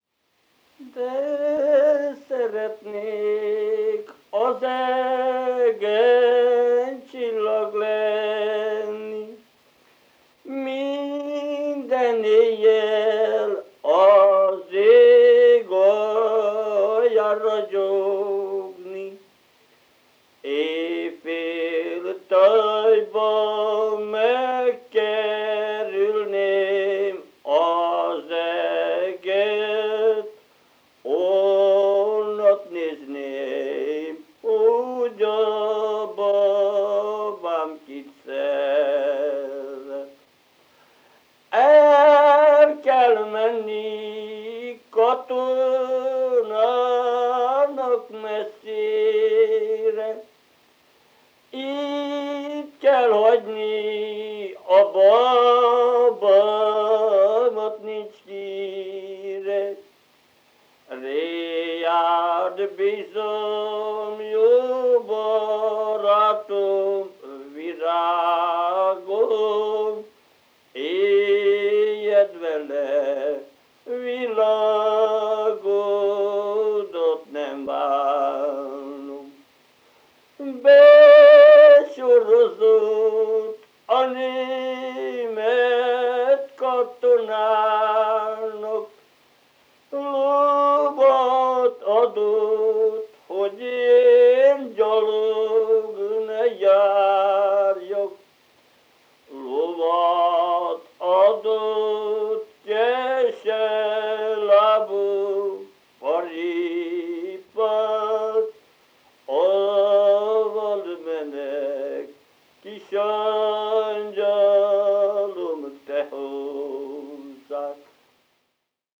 Multipart Music, Instrumentation of Sound, Instrumentalization of Sound, Sound and Society, Performance as Instrumentation, Tradition, Revival
Folk & traditional music